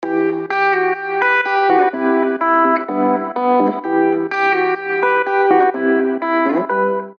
• Качество: 320, Stereo
гитара
мелодичные
без слов
короткие